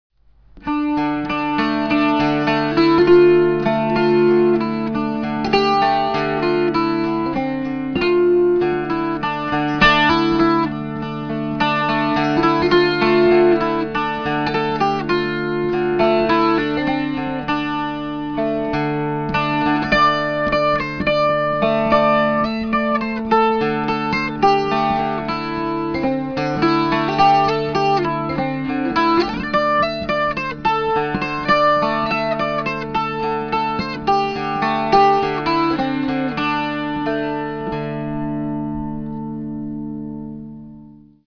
These are typical of the sounds my dulcimers produce. (The clips were recorded directly into a PC via the sound card using a small microphone mounted near a sound hole, and with no subsequent sound processing. A flat pick was used.)
Dulcimer #78 – Western Red Cedar and Tualang
Hourglass dulcimer with Western Red Cedar top and Tualang back, sides and fretboard.